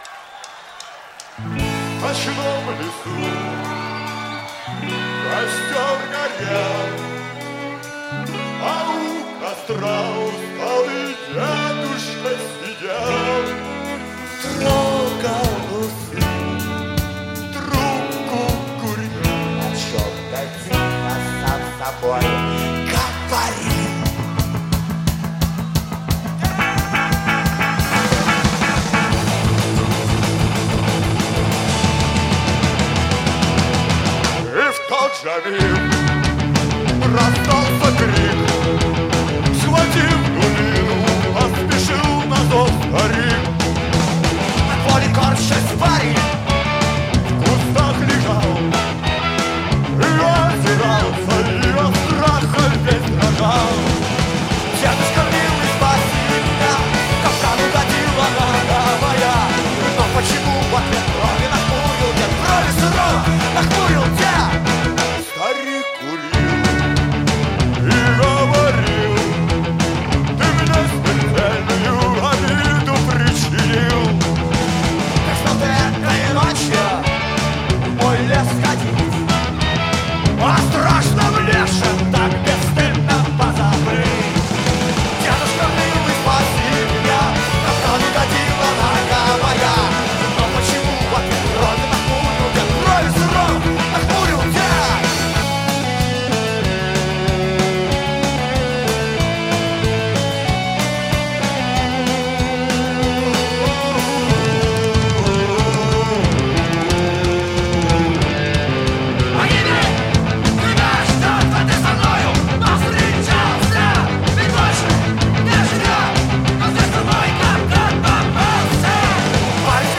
Жанр: Метал / Рок